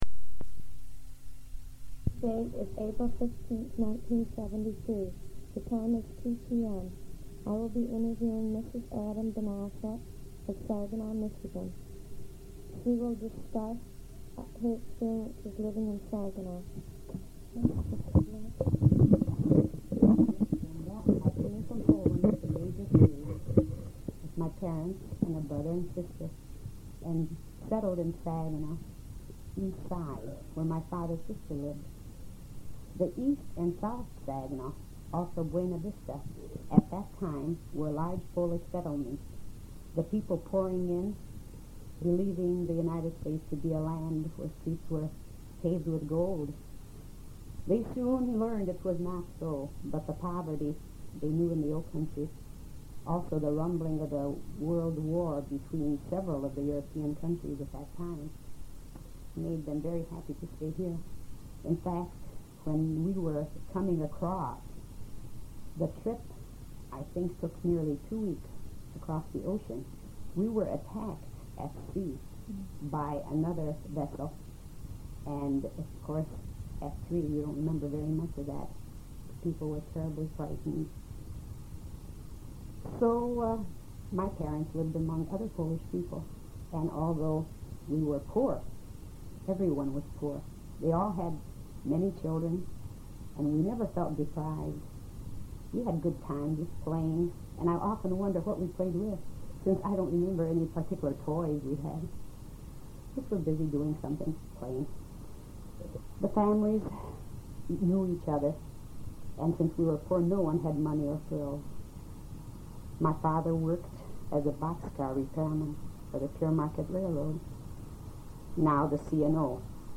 Interview devoted to life in Saginaw, beginning with her immigration from Poland.
Saginaw, MI
Audiocassette